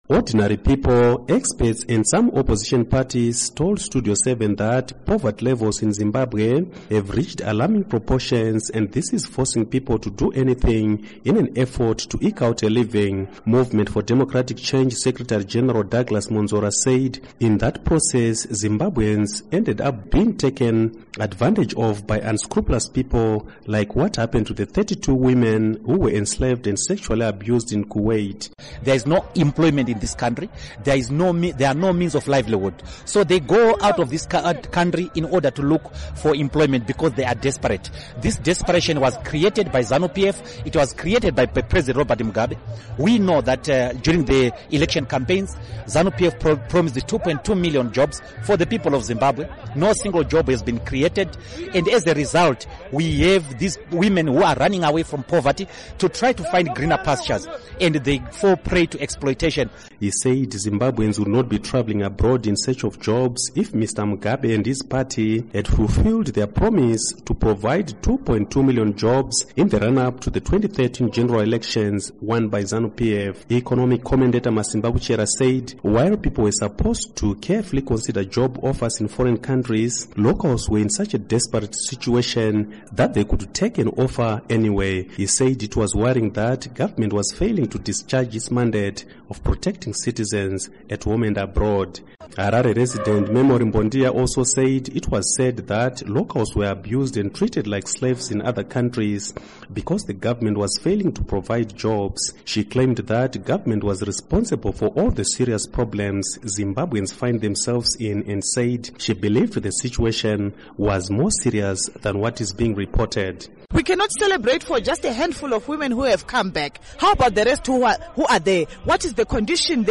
Report on Human Trafficking